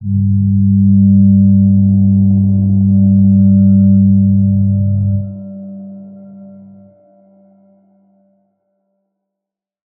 G_Crystal-G3-pp.wav